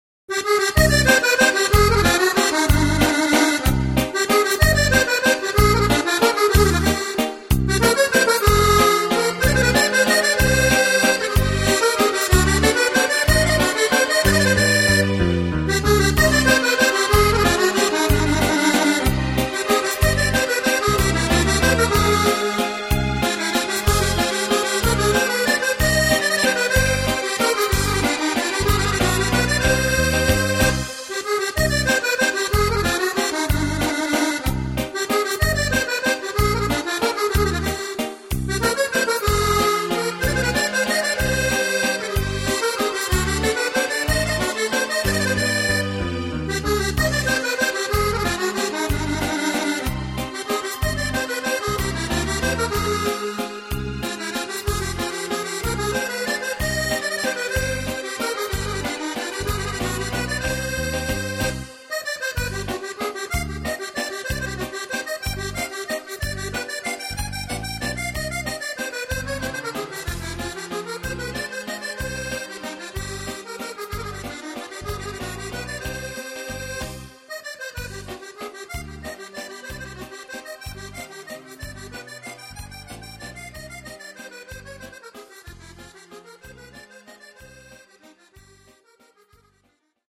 Valzer